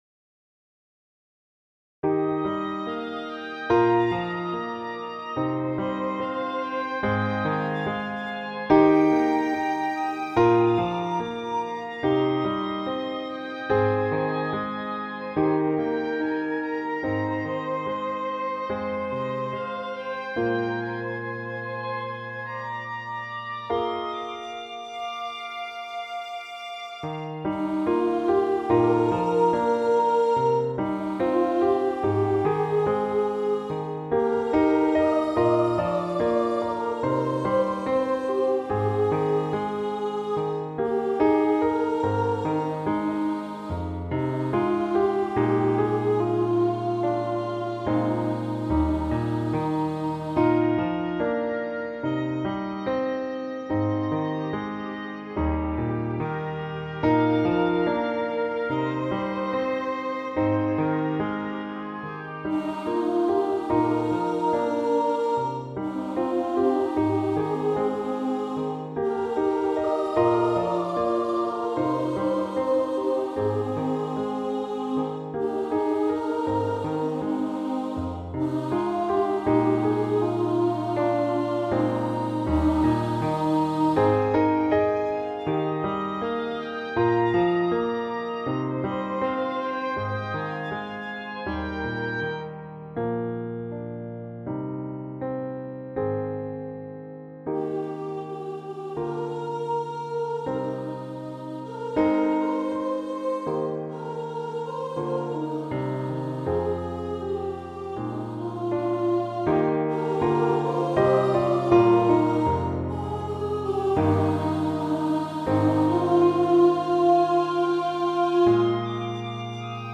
• Accompaniment: Clarinet, Flute, Oboe, Violin